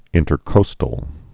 (ĭntər-kōstəl)